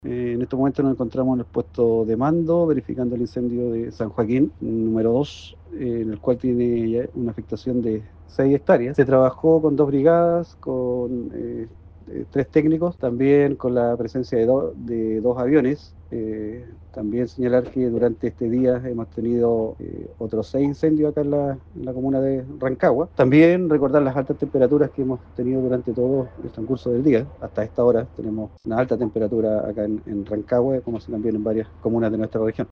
Escuchemos el reporte del director regional de la Conaf, Oscar Galdames: